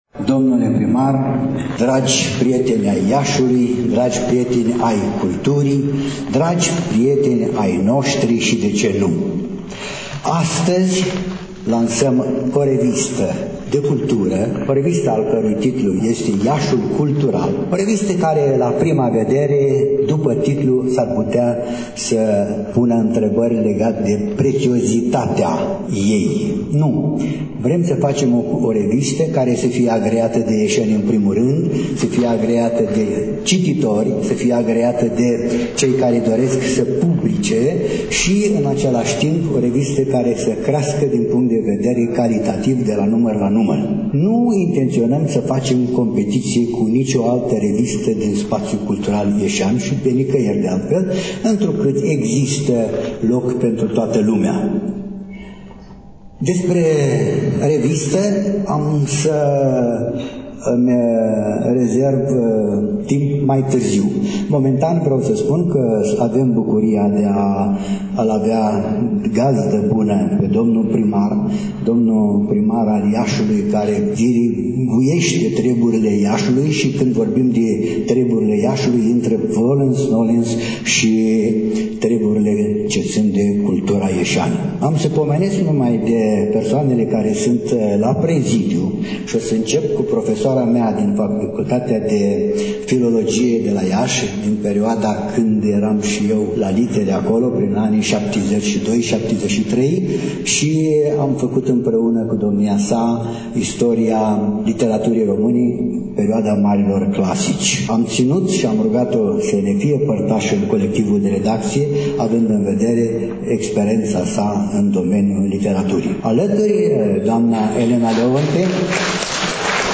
Reamintim că, astăzi, relatăm de la prezentarea primului număr al revistei de cultură și literatură „Iașul cultural”, o nouă revistă în peisajul cultural ieșean.